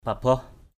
/pa-bɔh˨˩/